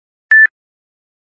walkBeep.mp3